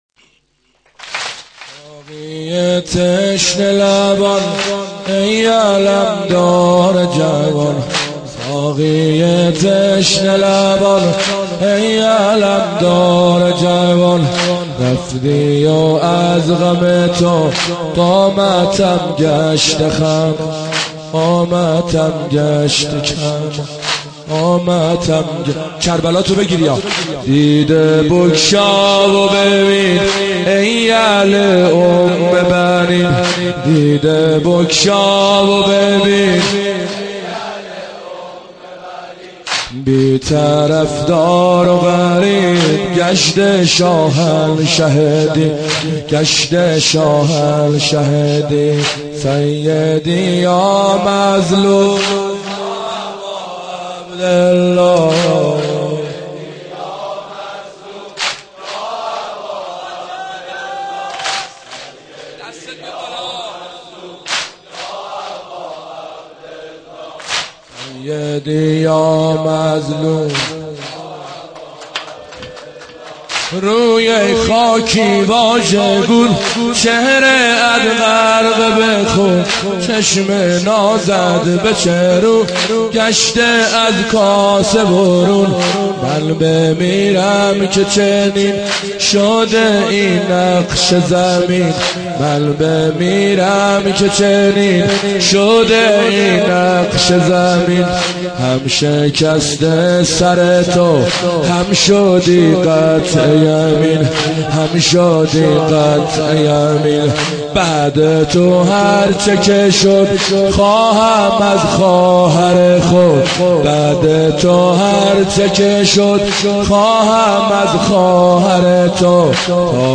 اشعار واحد شب نهم محرم با سبک حاج حسین سیب سرخی -( ساقی تشنه لبان،ای علمدار جوان )